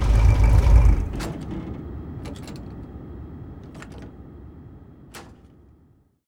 car-engine-stop-2.ogg